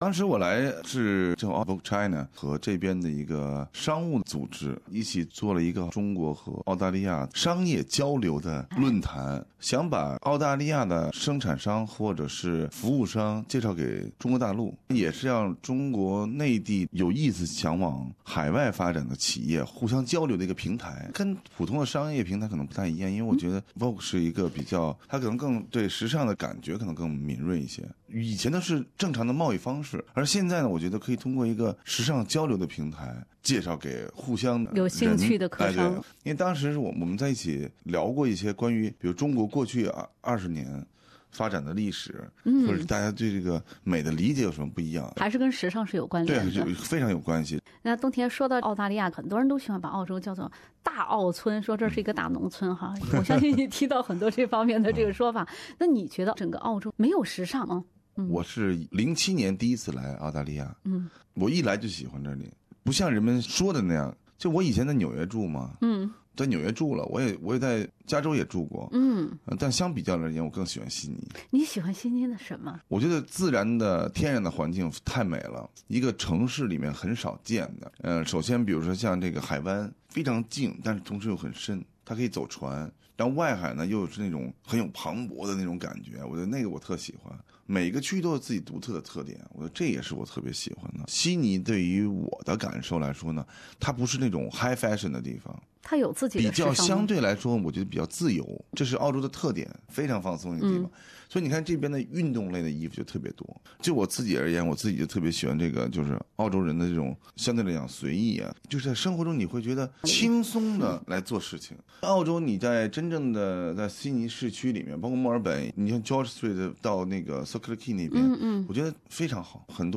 人物故事：懂时尚，更懂努力——中国“彩妆教父”李东田专访
PROFILE: CHINESE FASHION ICON LI DONGTIAN interview BY LI DONGTIAN Source: PROFILE: CHINESE FASHION ICON LI DONGTIAN interview BY LI DONGTIAN